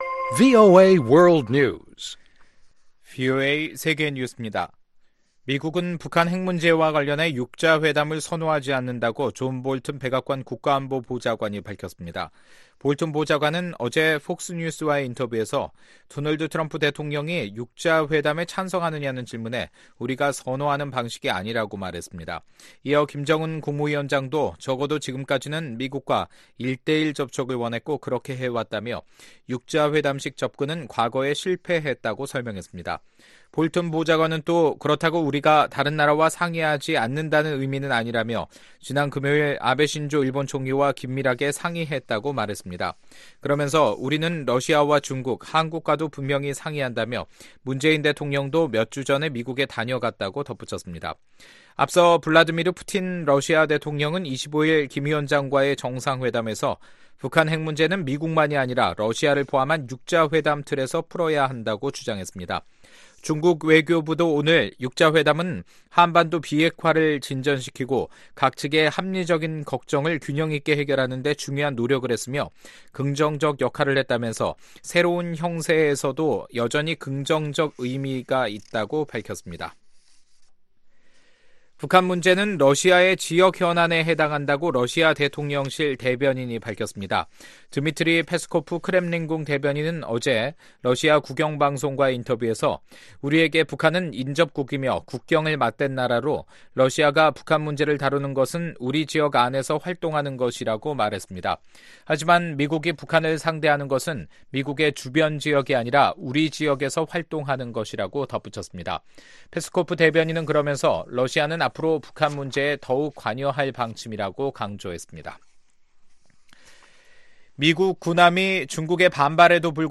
VOA 한국어 간판 뉴스 프로그램 '뉴스 투데이', 2019년 4월 29일 3부 방송입니다. 미국은 북한 핵 문제 해결과 관련해 과거의 실패한 6자회담을 선호하지 않는다고 존 볼튼 백악관 국가안보보좌관이 밝혔습니다. 미국 의회 의원들은 북한 정부가 미국인 대학생 오토 웜비어의 병원비 200만 달러를 청구한 데 대해 강하게 비난했습니다.